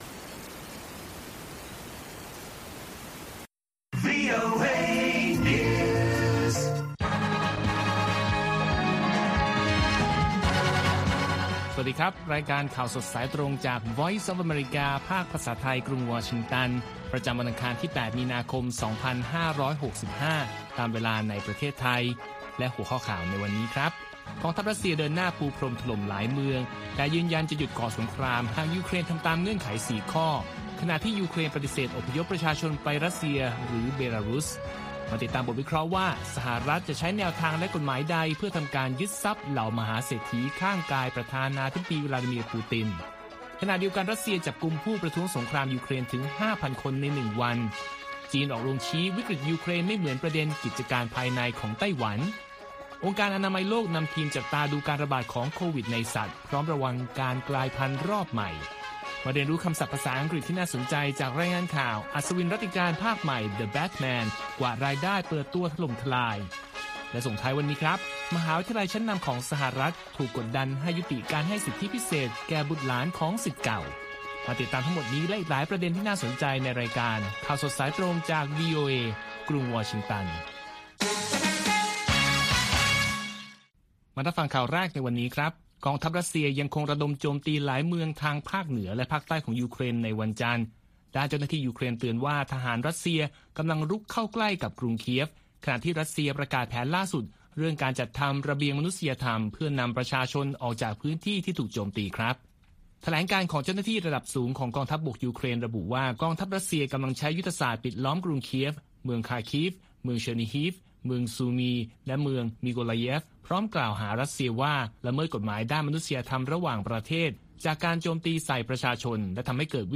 ข่าวสดสายตรงจากวีโอเอ ภาคภาษาไทย ประจำวันอังคารที่ 8 มีนาคม 2565 ตามเวลาประเทศไทย